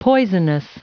Prononciation du mot poisonous en anglais (fichier audio)
Prononciation du mot : poisonous